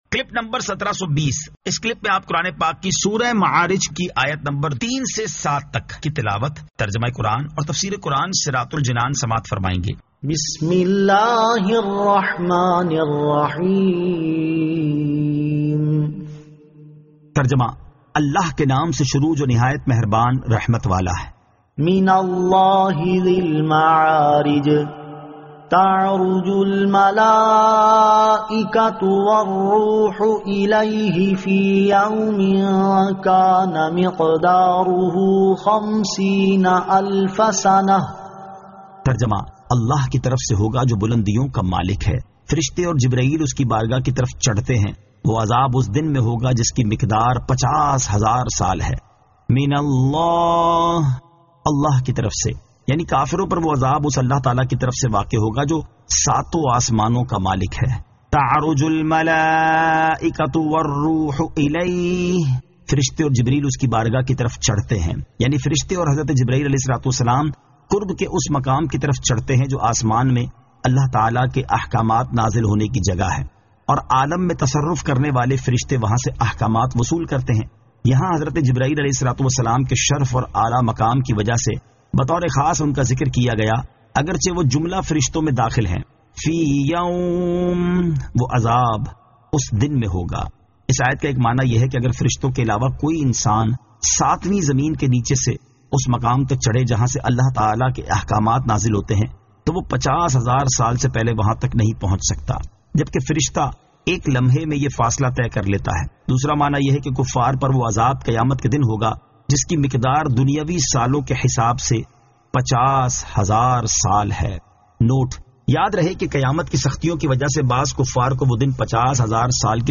Surah Al-Ma'arij 03 To 07 Tilawat , Tarjama , Tafseer